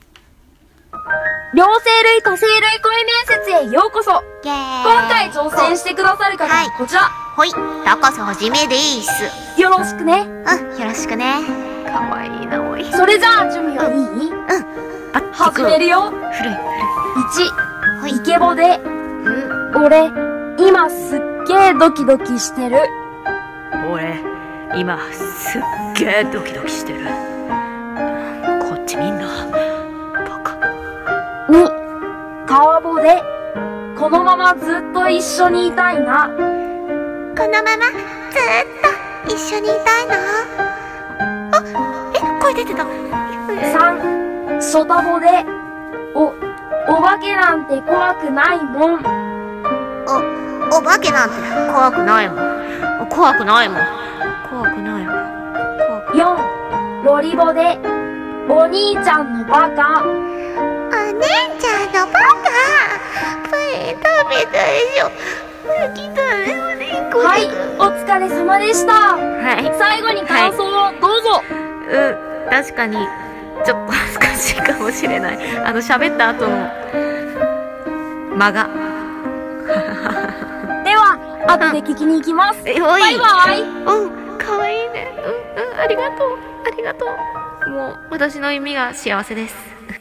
両声類・多声類声面接